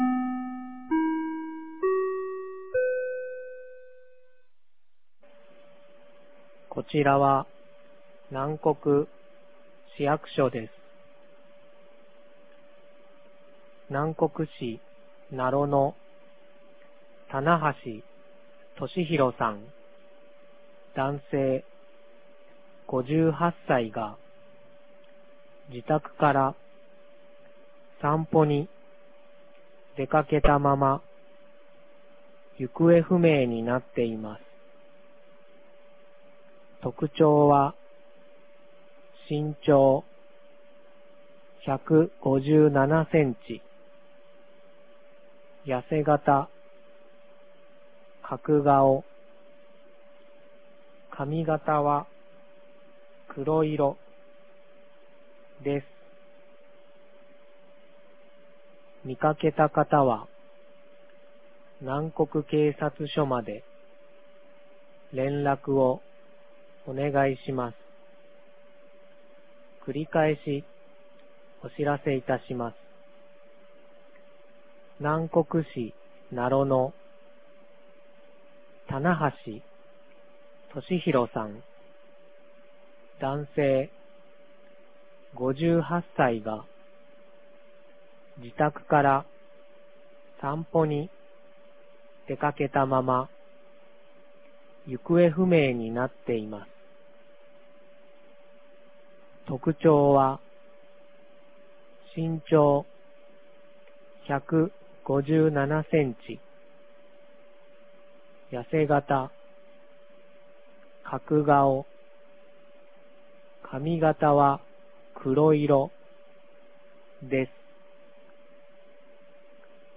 2023年08月30日 12時17分に、南国市より放送がありました。